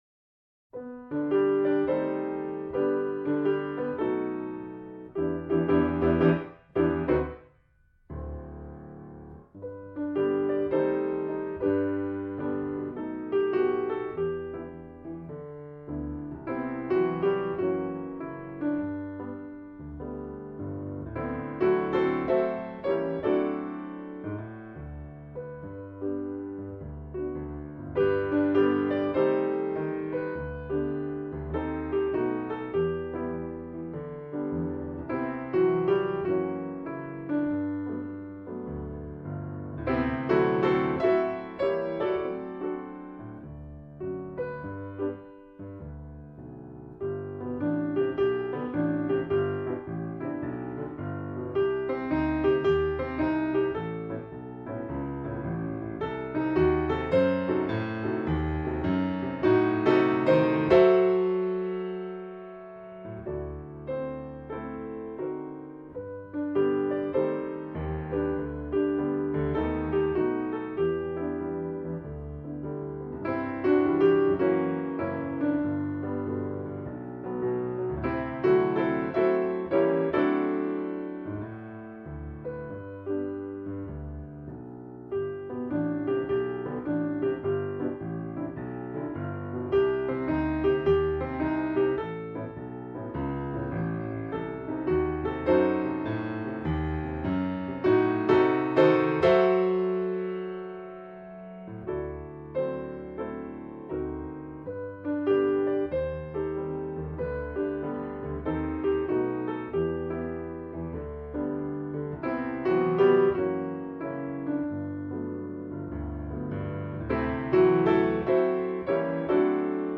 เพลงพระราชนิพนธ์